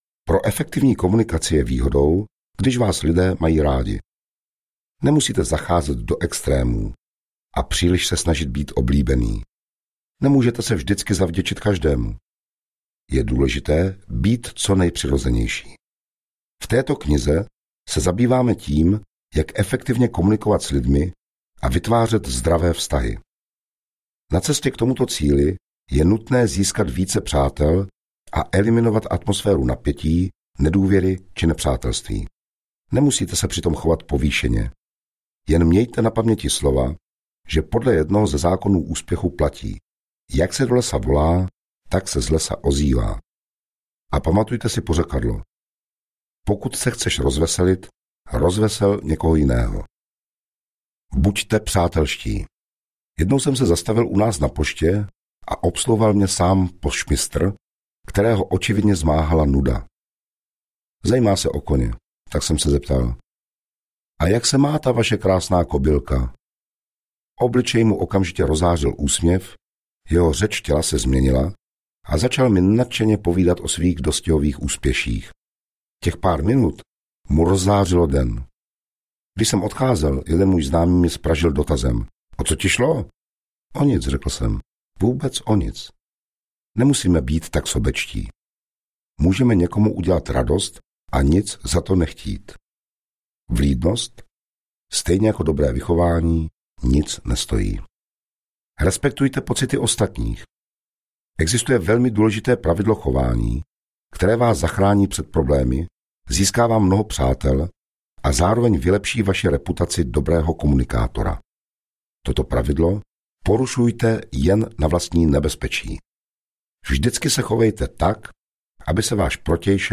Audio knihaKomunikací k vítězství
Ukázka z knihy